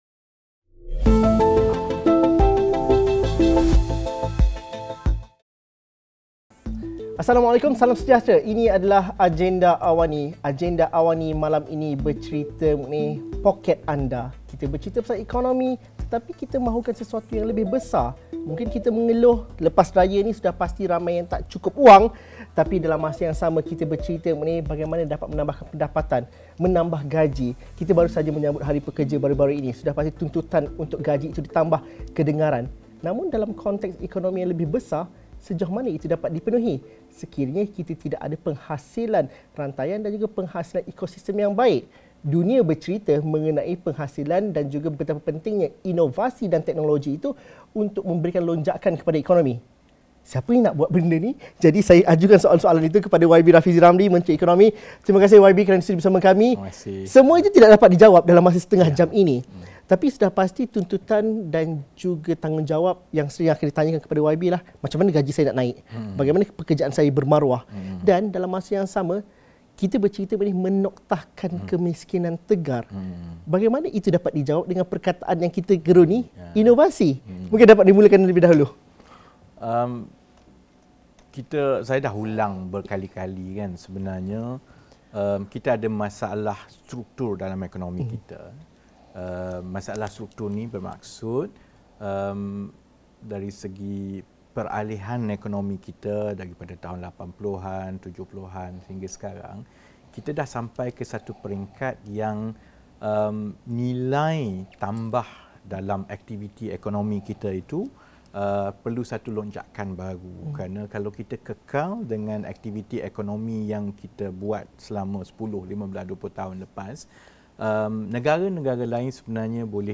Temu bual khas bersama Menteri Ekonomi, Rafizi Ramli.